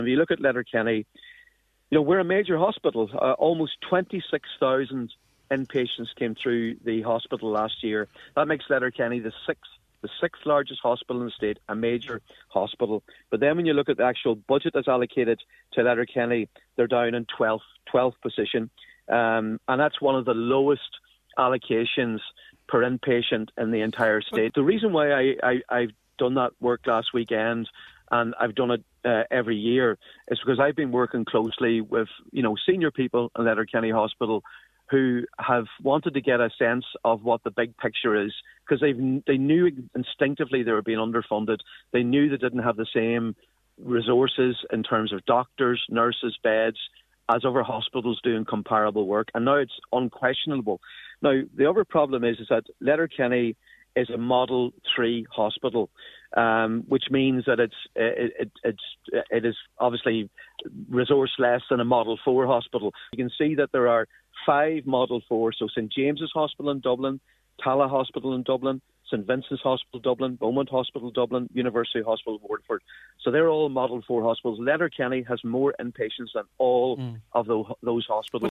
Deputy MacLochlainn, speaking earlier on the Nine til Noon Show, says the underfunding of Letterkenny University Hospital is totally unacceptable: